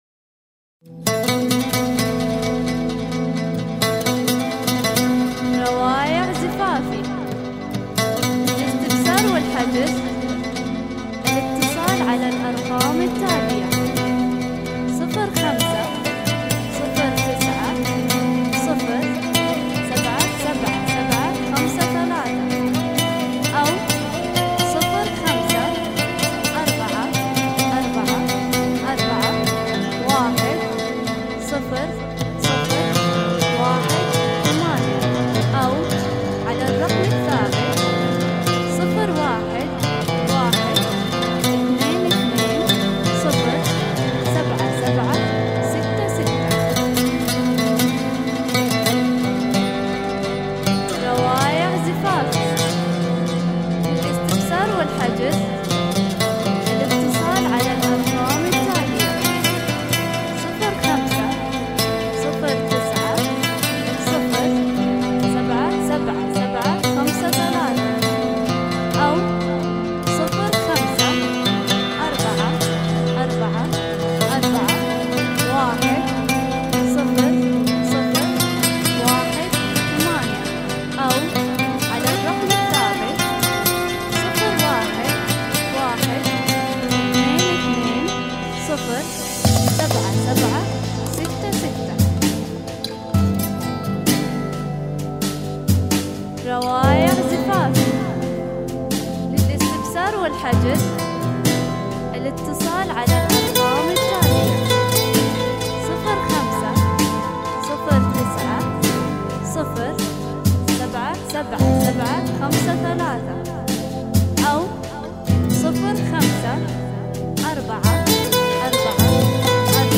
زفات موسيقية